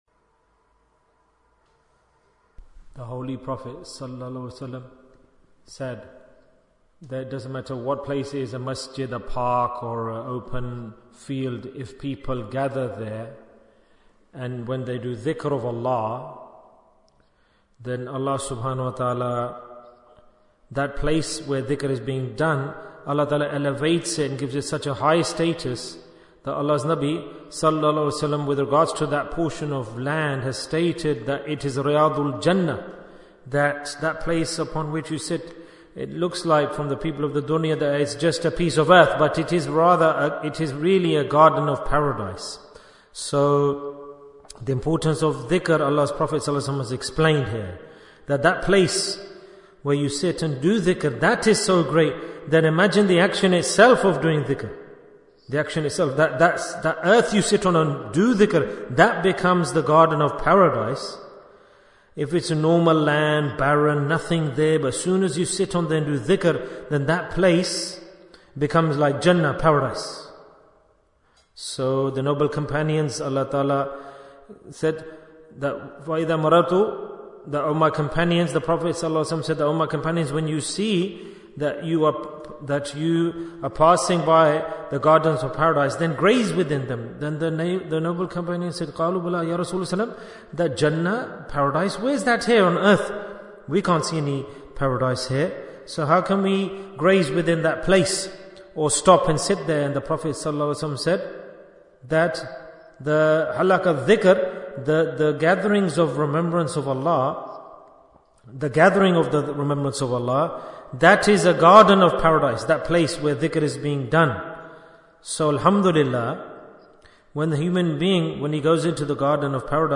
Talk before Dhikr 136 minutes25th November, 2024